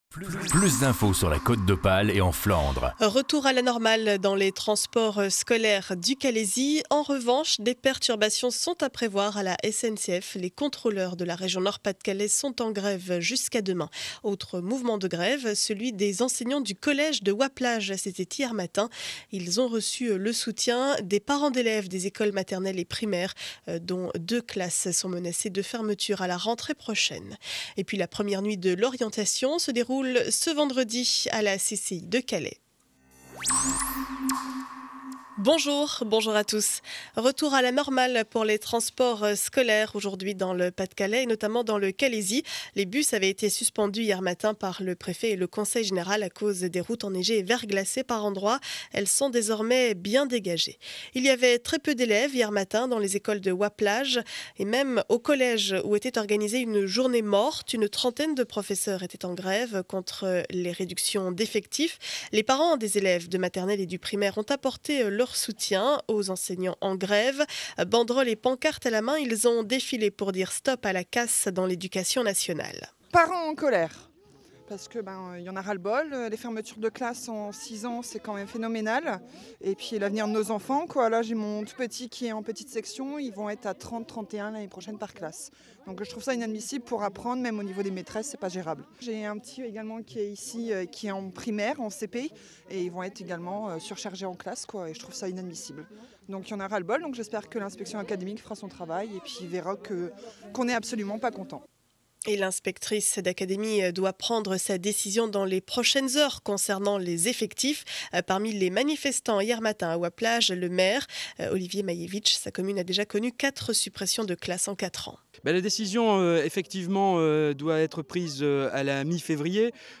Journal du mardi 7 février 2012 7 heures 30 édition du Calaisis.